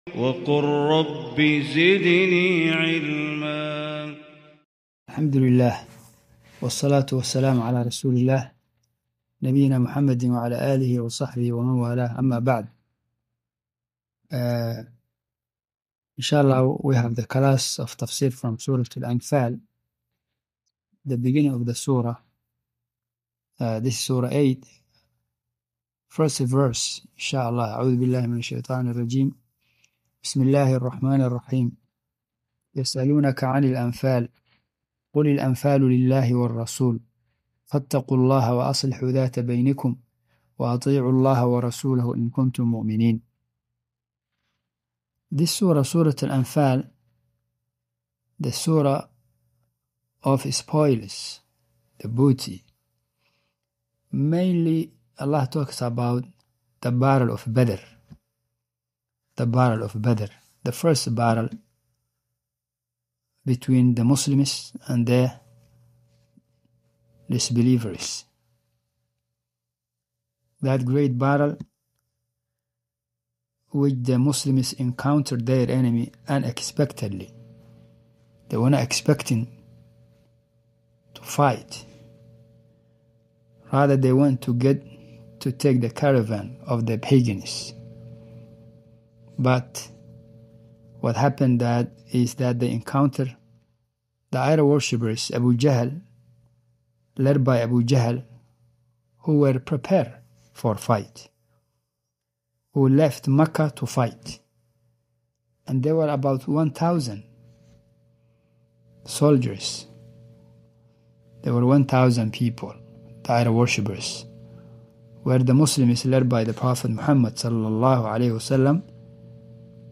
Surah Anfal 00:00 Sorry, no results.Please try another keyword Tafsir Quran